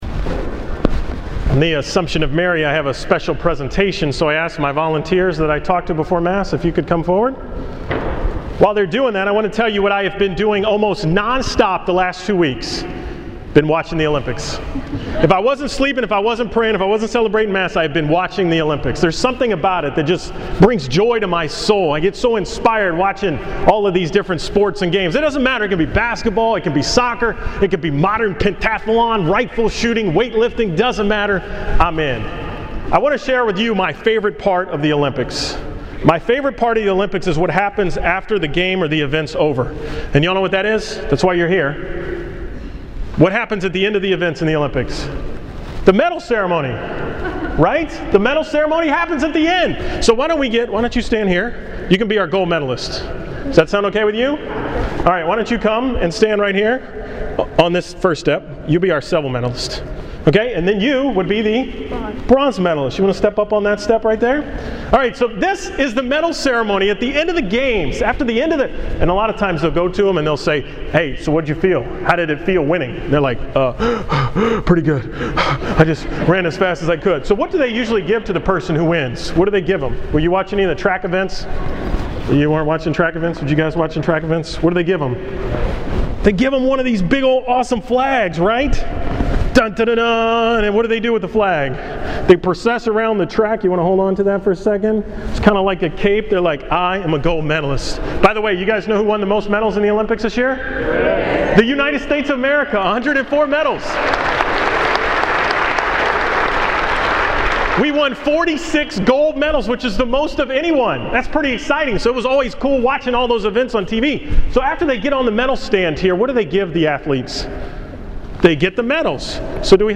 Assumption of Mary Homily
From August 15, 2012 on the feast of the Assumption of Mary